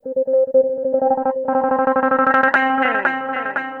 Track 13 - Clean Guitar Wah 03.wav